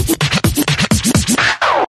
KSHMR_Vinyl_Scratch_06_128